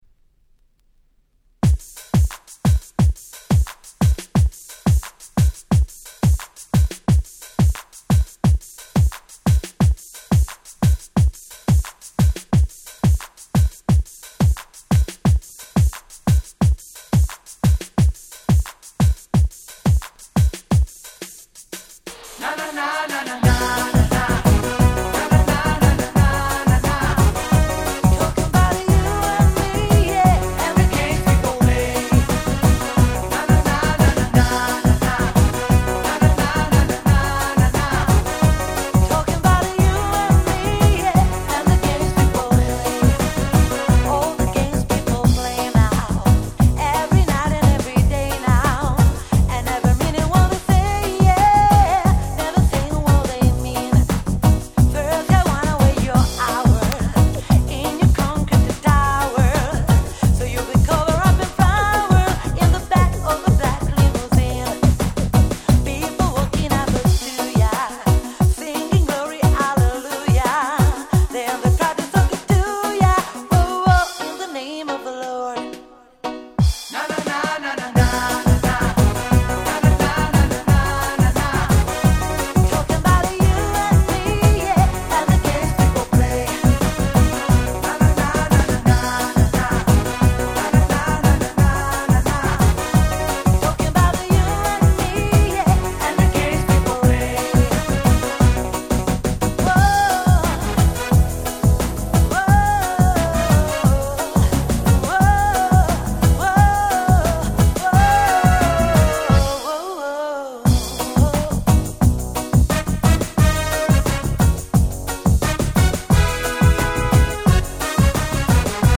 【Media】Vinyl 12'' Single
94' Nice Ground Beat (Ace Beat)!!